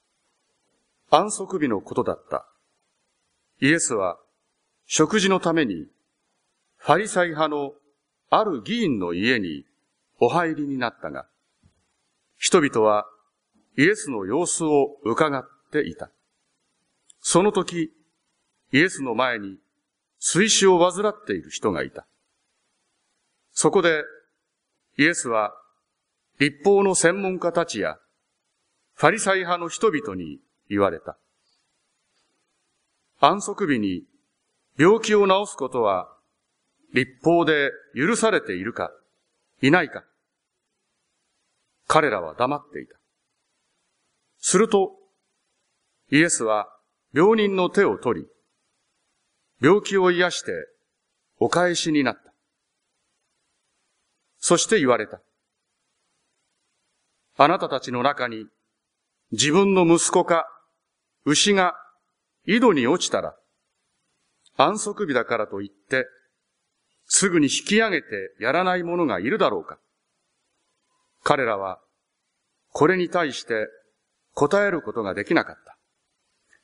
聖書朗読(0.4MB)